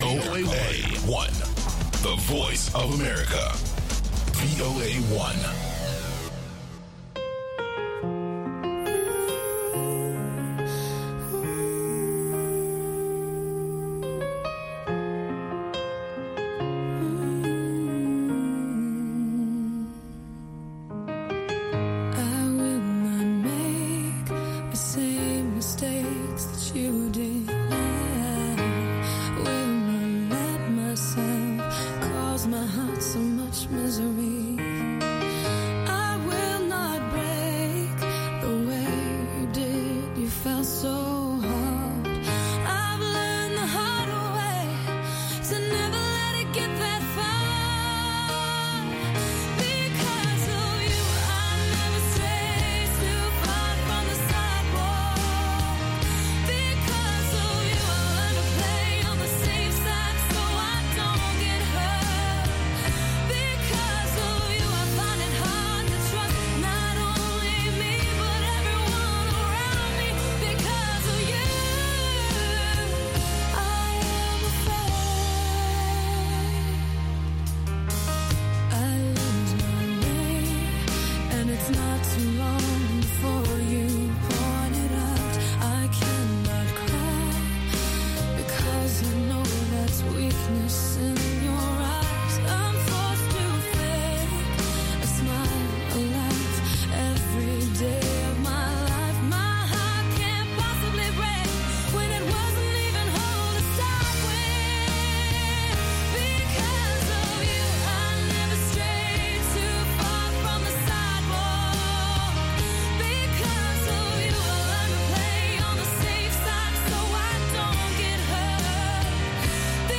You’ll also hear Classic Soul from legendary musicians who have inspired a the new generation of groundbreaking artists.